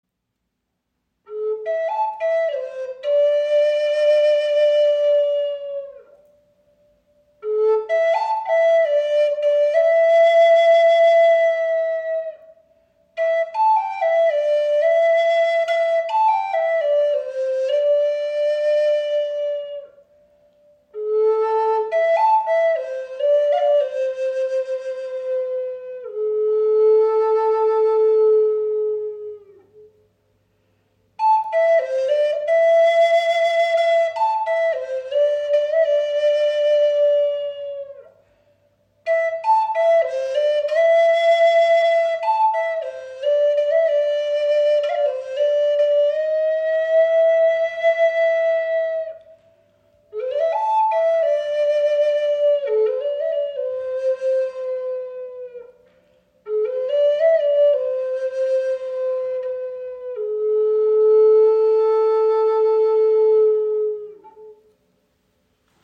Die Sparrow Hawk Flöte in A-Moll | Aromatische Zeder | 48 cm
Sie wird entweder aus heimischem Walnussholz oder aus spanischer Zeder gefertigt – beide Hölzer verleihen ihr eine klare, warme Klangfarbe.
Ihr beruhigender, mittlerer Ton macht sie besonders angenehm für Anfänger und einfühlsame musikalische Momente.
High Spirits Flöten sind Native American Style Flutes.